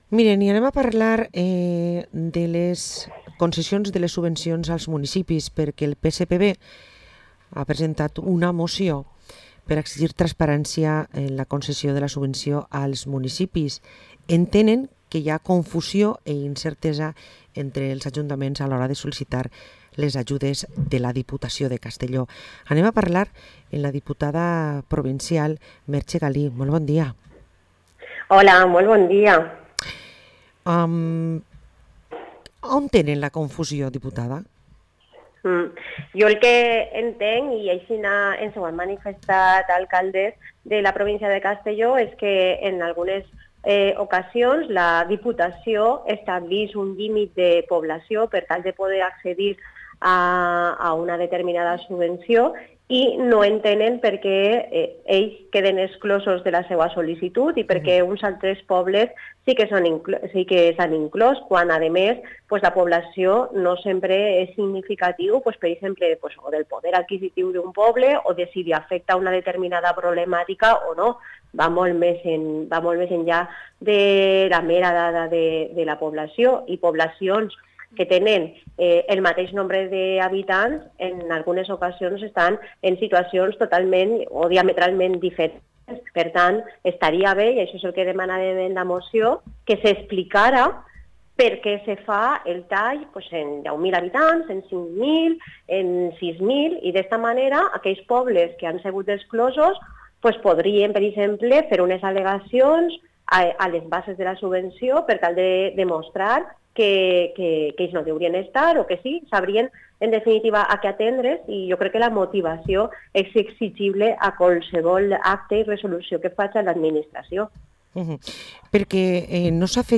Parlem amb la diputada provincial del PSPV Merche Galí